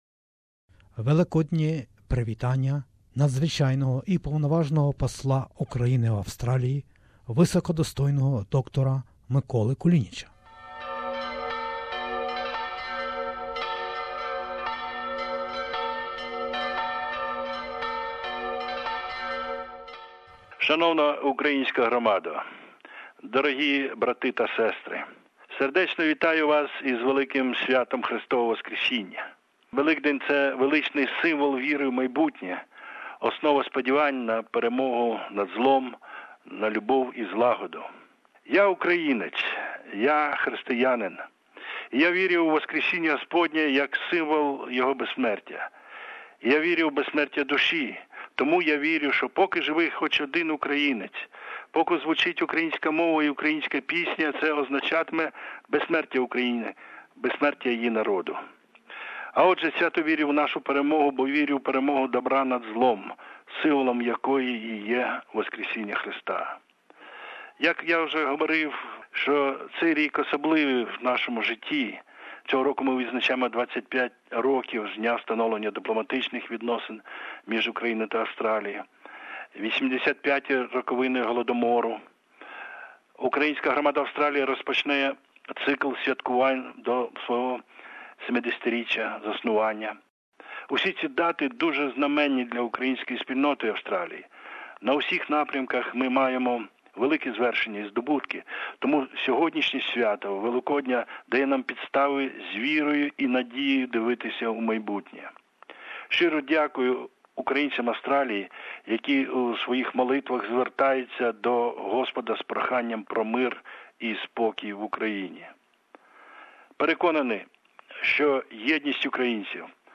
EASTER GREETING OF HIS EXELLENCY DR MYKOLA KULINICH, AMBASSADOR OF UKRAINE TO AUSTRALIA